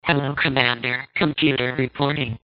Intellivision_-_Space_Spartans_-_Hello_Commander,_Computer_reporting.mp3